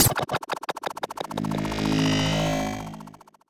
Robot Whoosh Notification 4.wav